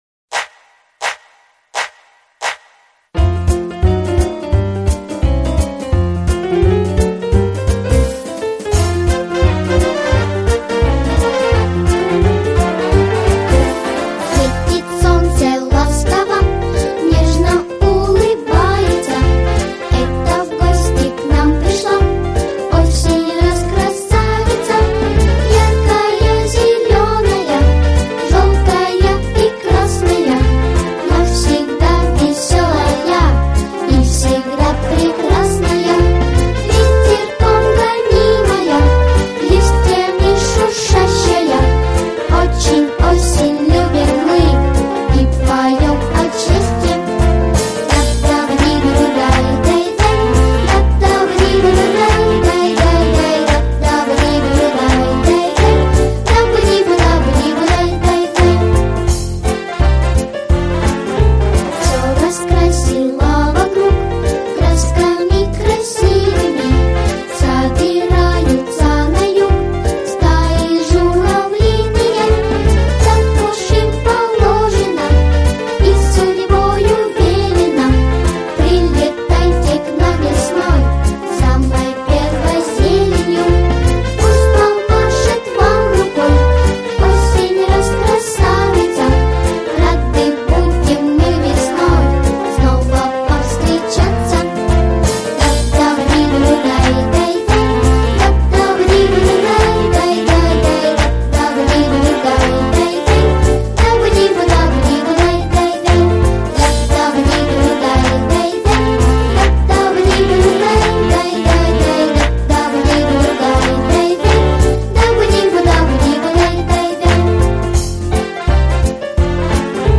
Песенки про осень
для детского сада